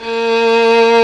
Index of /90_sSampleCDs/Roland - String Master Series/STR_Violin 1-3vb/STR_Vln3 % marc
STR VLN3 A#2.wav